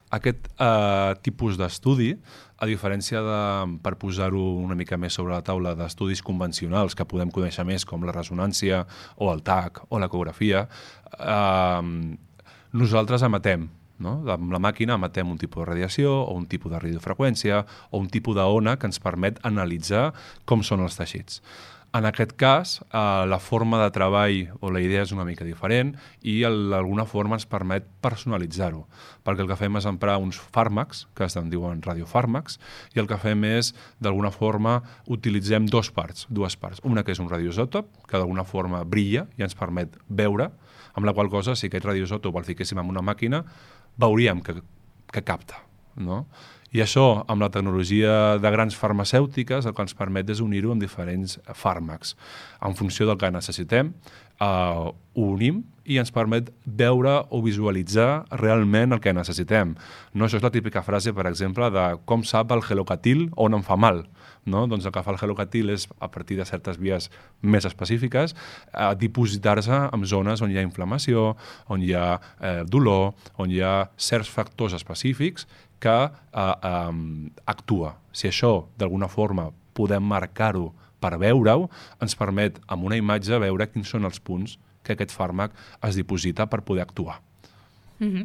En una entrevista a Ràdio Capital